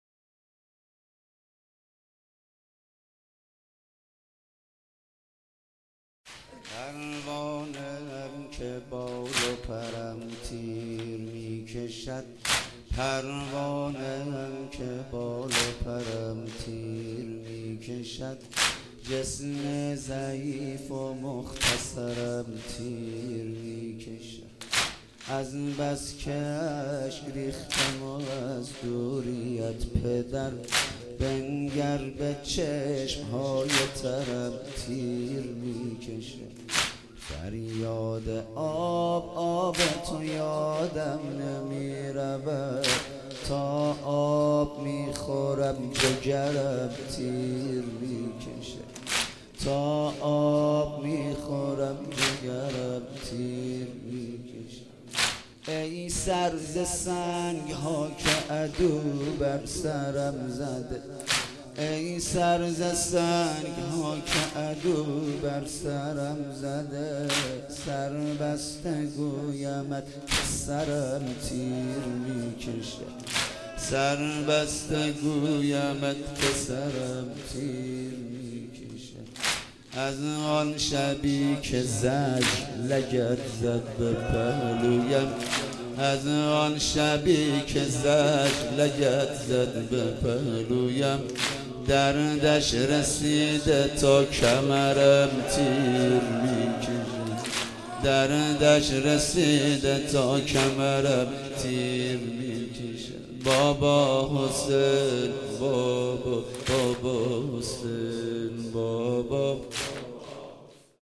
شب چهارم صفر 97 - واحد - پروانه ام که بال و پرم
صفر المظفر